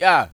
kits/Southside/Vox/Yahh.wav at ts
Yahh.wav